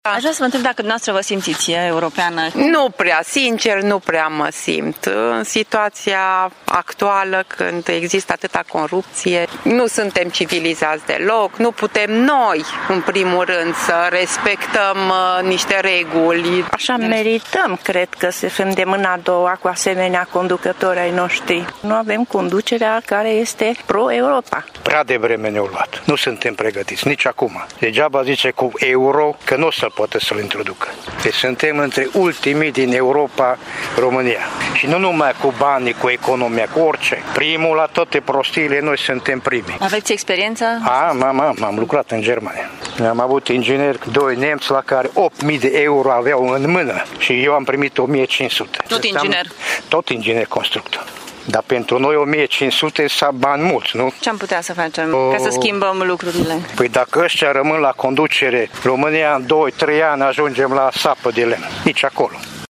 Târgumureșenii au dificultăți să se simtă europeni, mai ales din cauza corupției și a imaginii negative pe care chiar noi o promovăm în exterior: